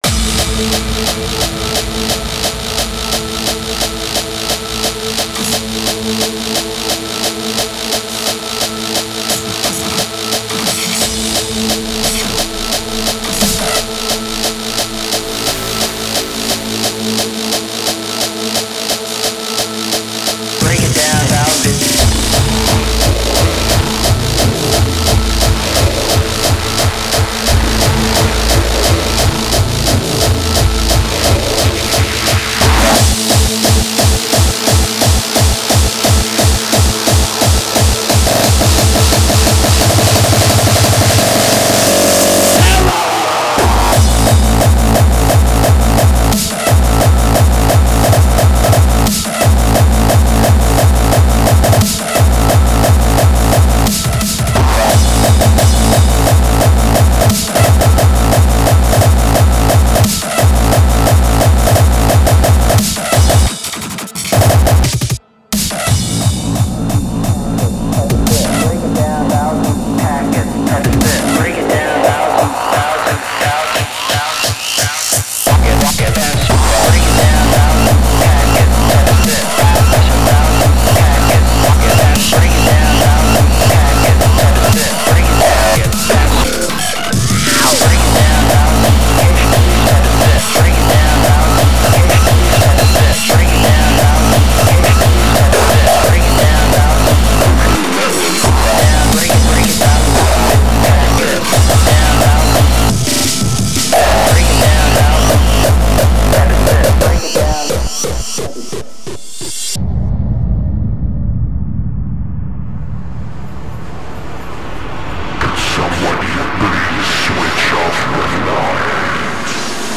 data/music/Japanese/J-Core